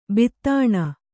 ♪ bittāṇa